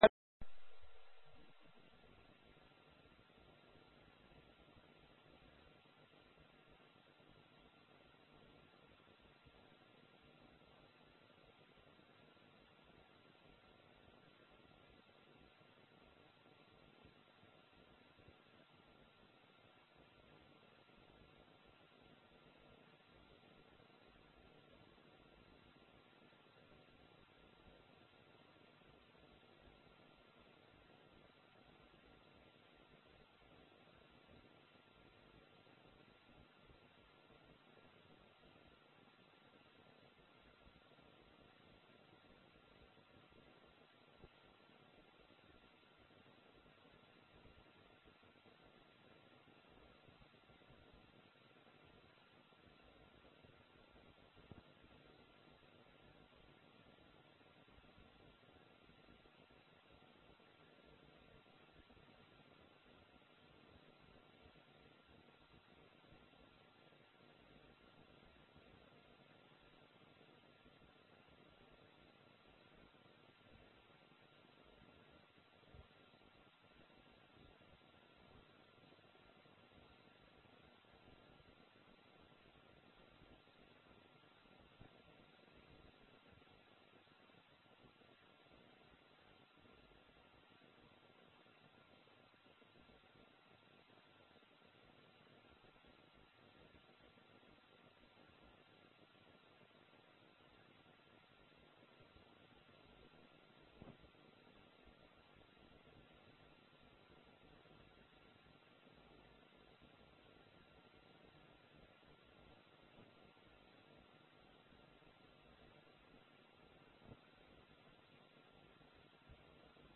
أخبار الخائفين من رب العالمين - خطب الجمعه